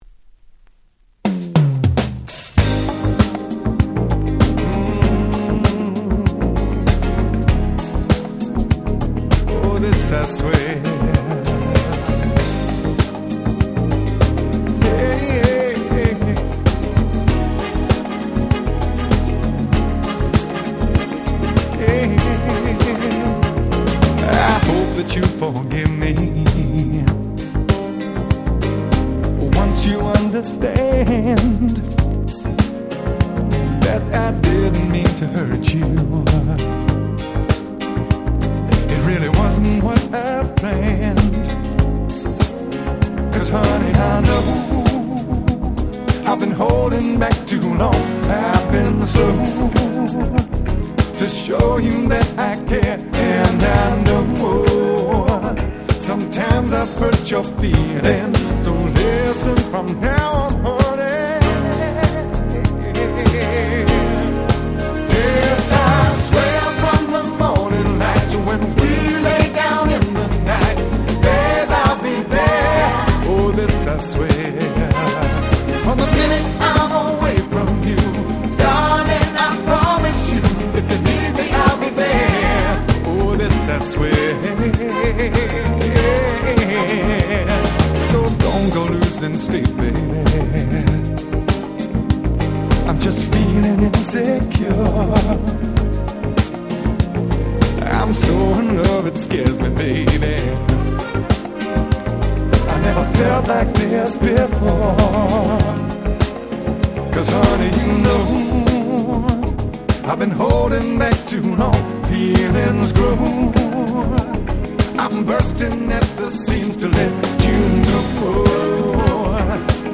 93' Super Nice UK Soul / R&B !!
爽快なメロディーが堪りません！